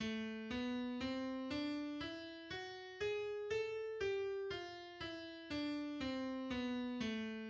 mineur mélodique
L’échelle de la mineur mélodique est :
• gamme ascendante : la, si, do, ré, mi, fa♯, sol♯, la.
• gamme descendante : la, sol, fa, mi, ré, do, si, la.